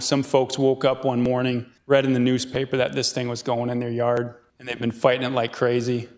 Republican Rep. Aaron Aylward (ail-word) from Harrisburg voiced concerns about the site selection.